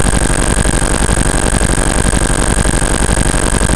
Soundspack 05 (8bit SFX 01-... / SFX / Laser
Laser_02.mp3